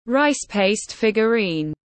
Rice paste figurine /raɪs peɪst ˈfɪgjʊriːn/